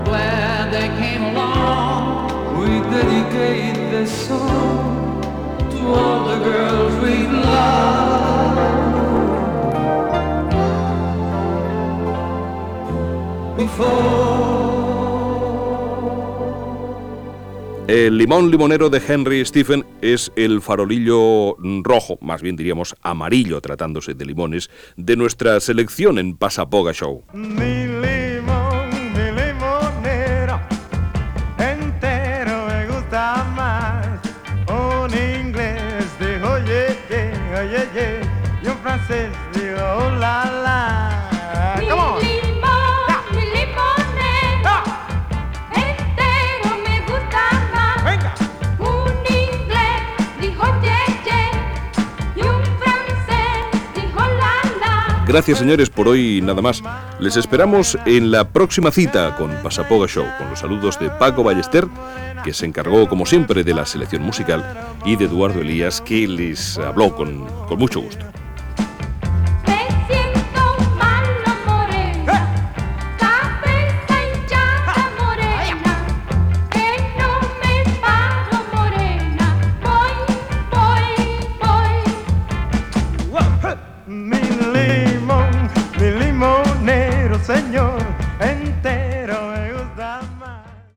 Tema musical i presentació del següent i comiat
Musical